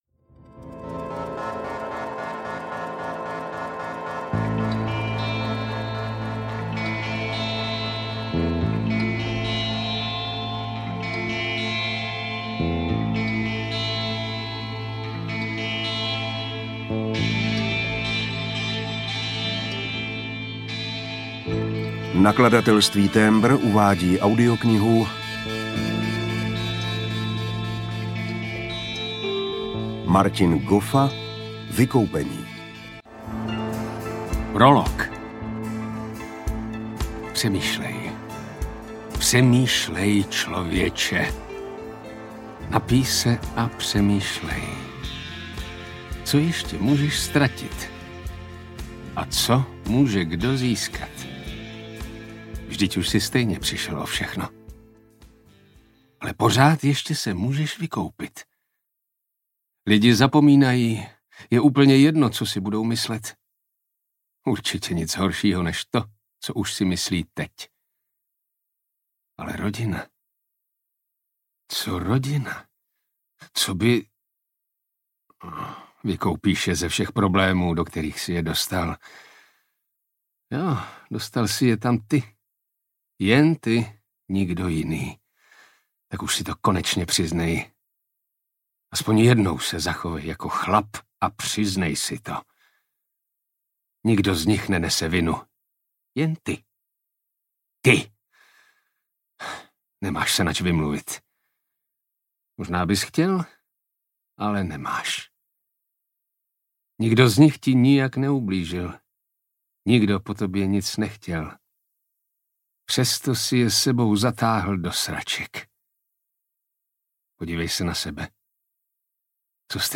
Vykoupení audiokniha
Ukázka z knihy